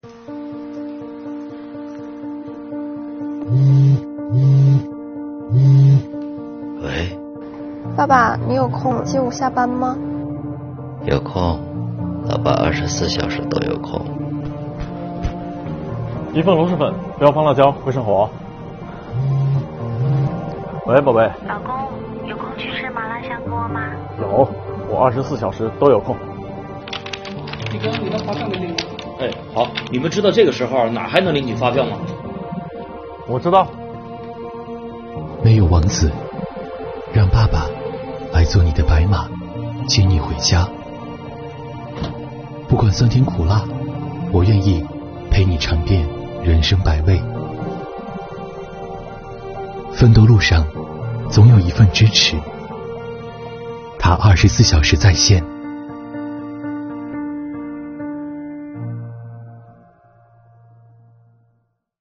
公益广告 | 24小时自助办税 让爱不打烊